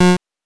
beep_7.wav